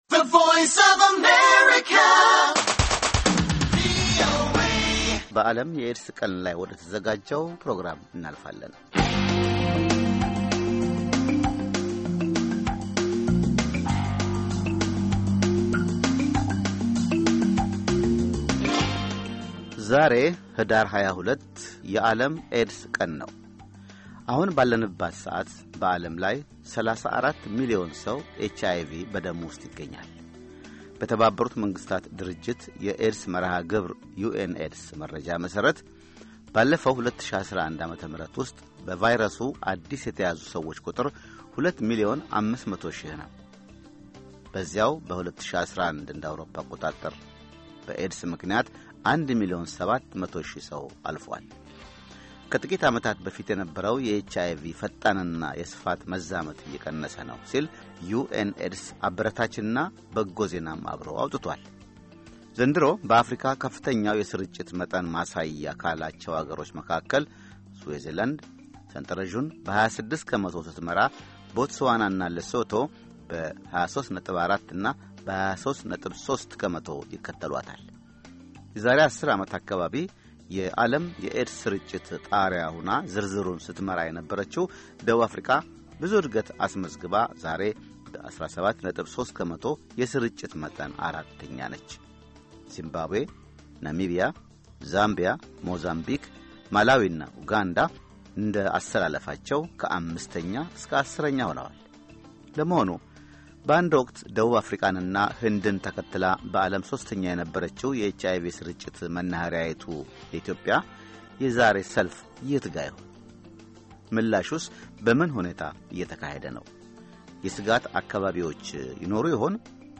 WAD - 2012 Panel Discussion